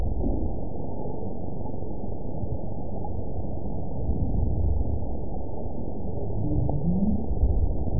event 917977 date 04/24/23 time 18:13:37 GMT (2 years, 7 months ago) score 8.71 location TSS-AB04 detected by nrw target species NRW annotations +NRW Spectrogram: Frequency (kHz) vs. Time (s) audio not available .wav